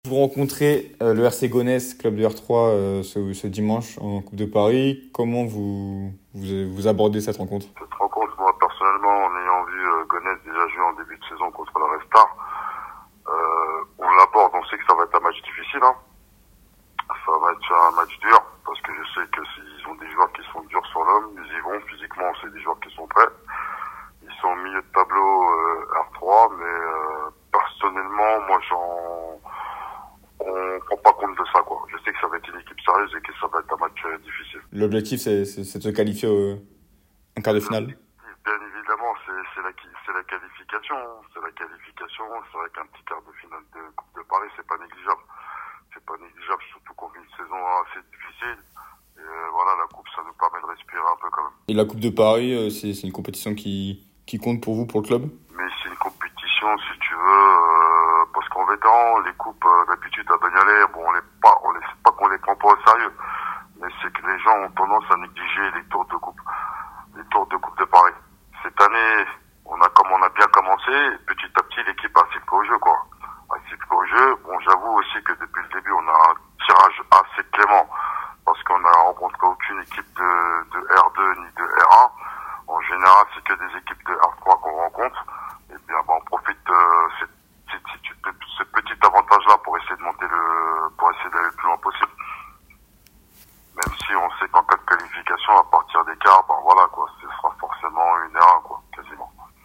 L’interview